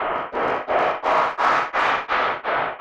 Index of /musicradar/rhythmic-inspiration-samples/85bpm